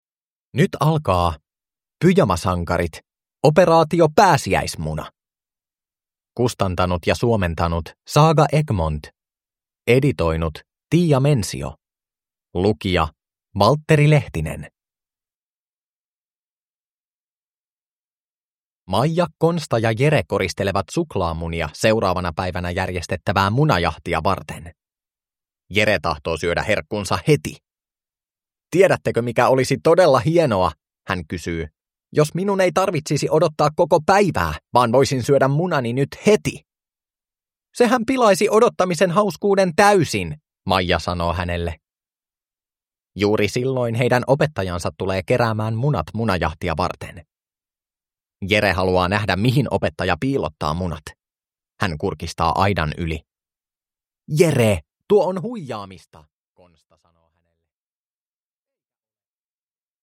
Pyjamasankarit – Operaatio pääsiäismuna – Ljudbok